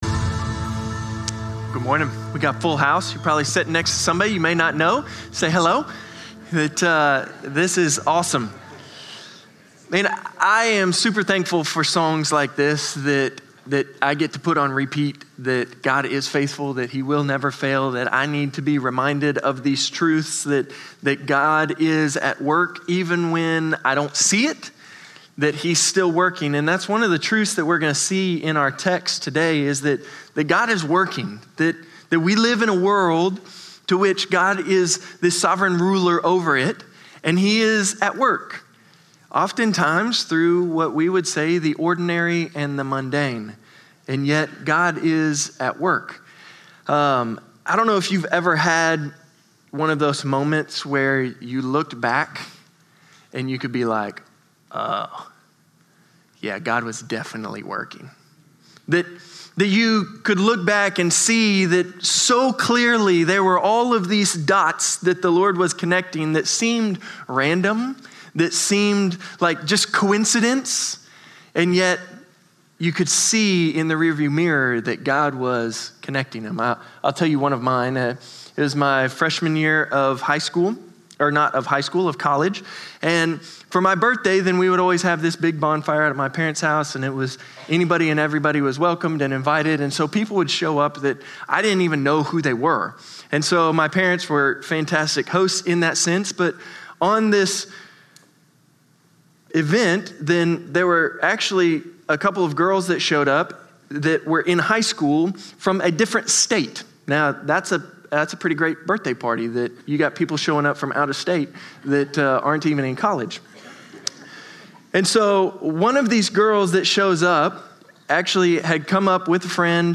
Norris Ferry Sermons Sept. 14, 2025 -- The Book of Ruth -- Ruth 2:1-13 Sep 14 2025 | 00:33:18 1x 00:00 / 00:33:18 Subscribe Share Spotify RSS Feed Share Link Embed